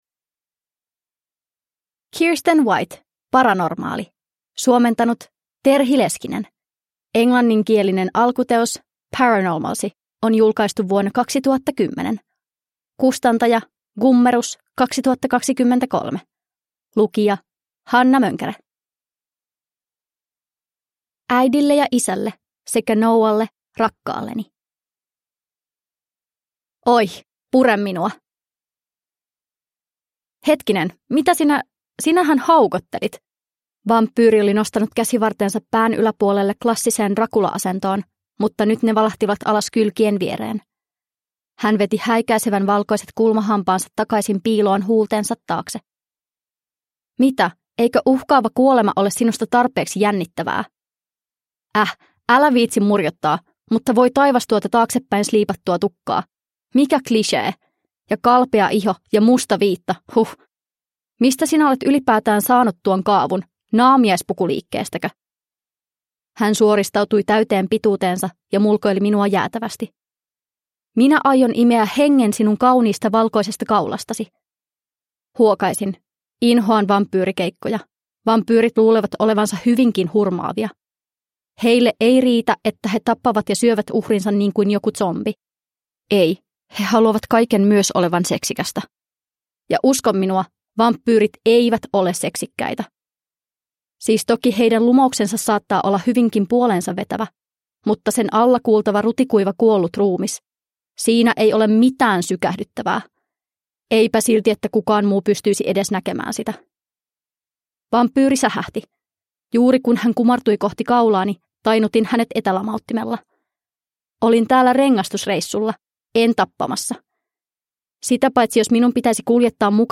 Paranormaali – Ljudbok